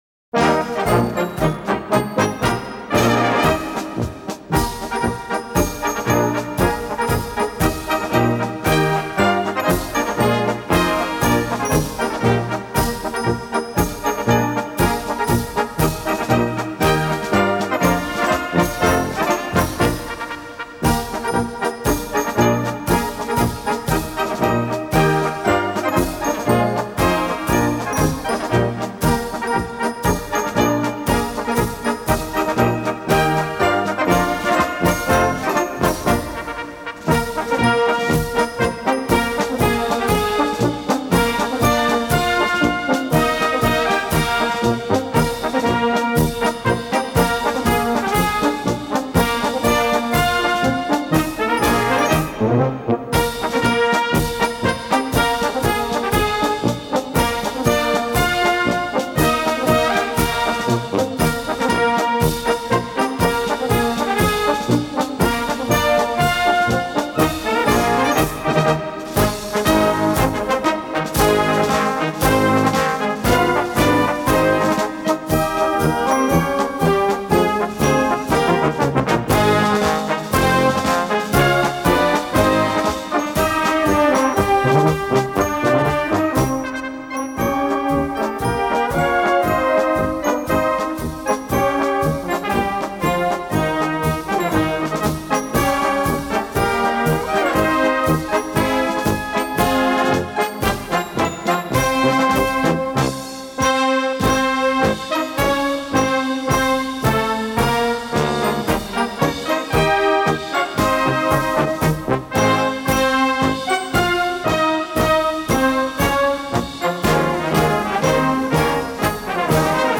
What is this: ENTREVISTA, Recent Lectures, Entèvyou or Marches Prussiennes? Marches Prussiennes